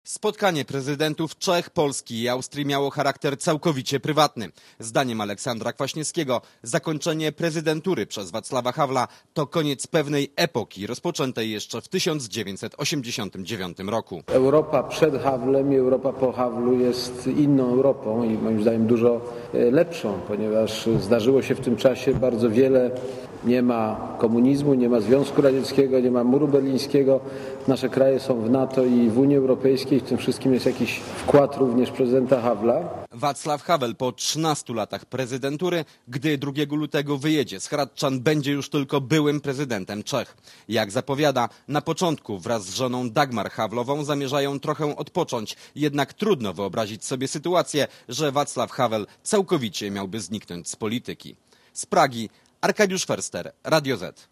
(RadioZet) Źródło: (RadioZet) Posłuchaj relacji (0,4 MB) Oceń jakość naszego artykułu: Twoja opinia pozwala nam tworzyć lepsze treści.